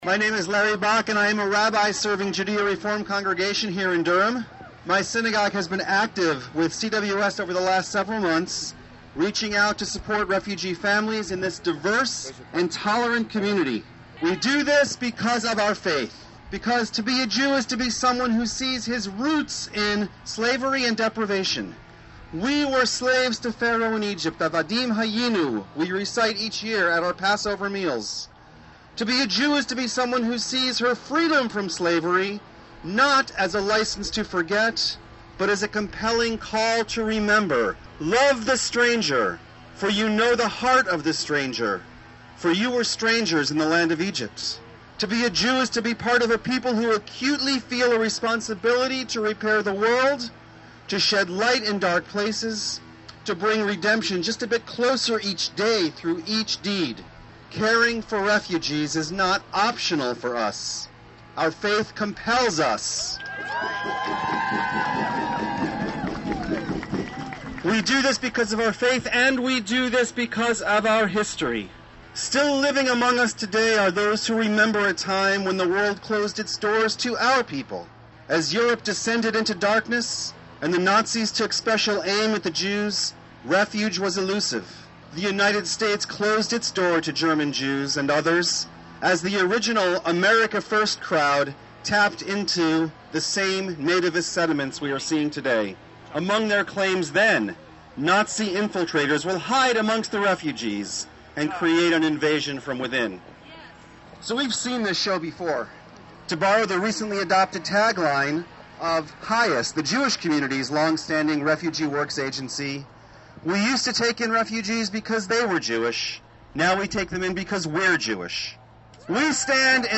Demonstrations began locally on Friday morning, as about 150 people gathered in downtown Durham for a rally organized by Church World Service.